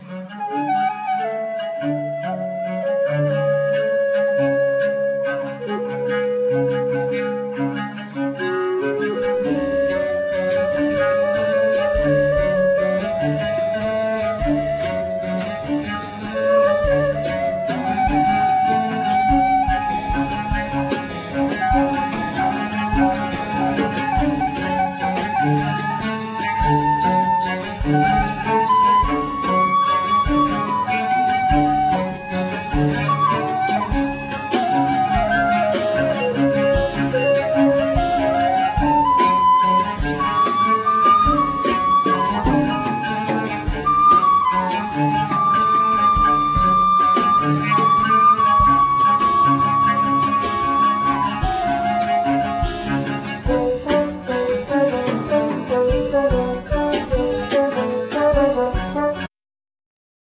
Compositions,Tuba,Saqueboute,Trombone,Voice
Flute,Traverso,Voice
Harpsichord
Viola da gamba
Percussions